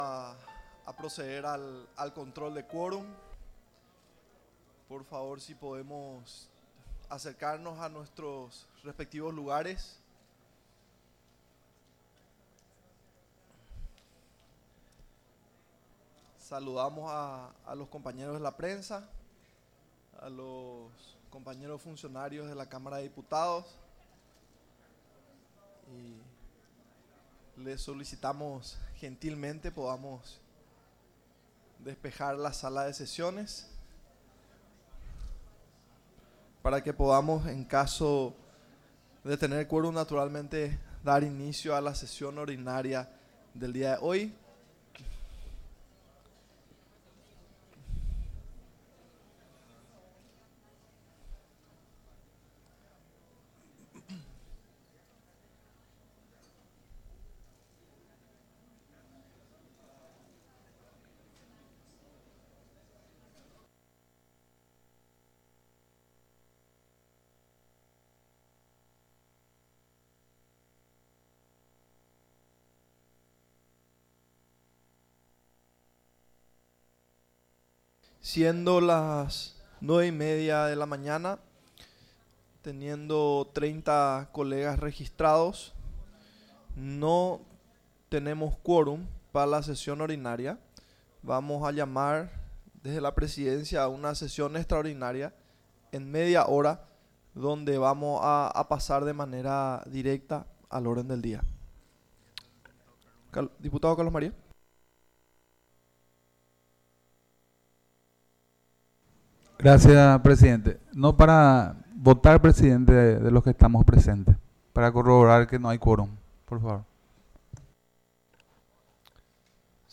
Sesión Ordinaria, 27 de setiembre de 2023